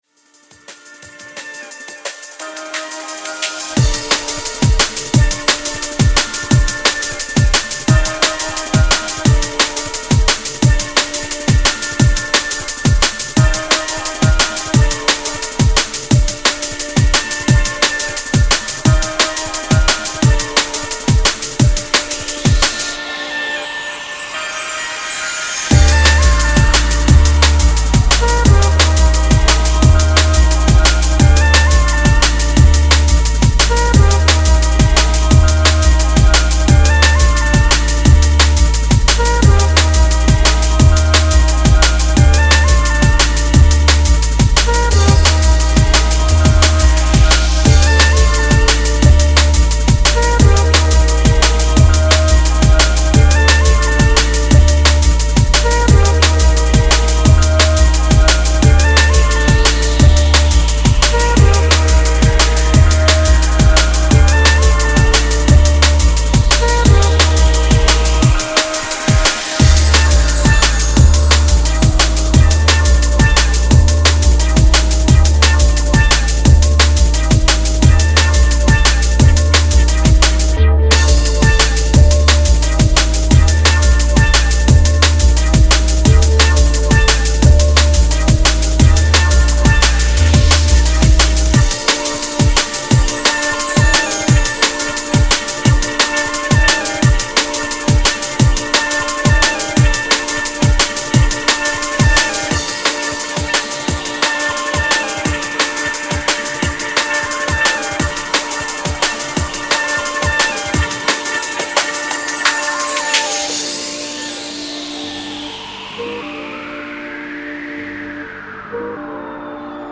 Styl: Drum'n'bass Vyd�no